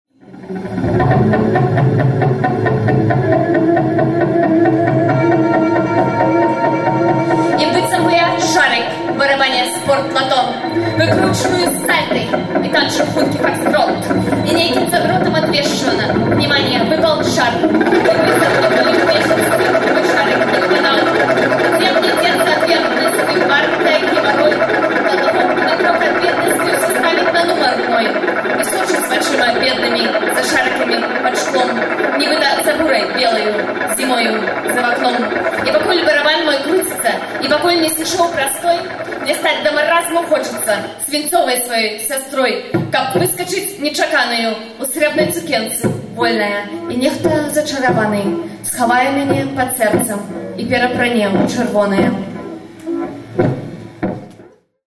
Вальжына Морт чытае свае вершы
(гітара + harmonizer + delay)
Гэта фрагмэнты выступу на фэсце “няіснае беларускае мастацтва” (20.05.06, Кемніц, Нямеччына).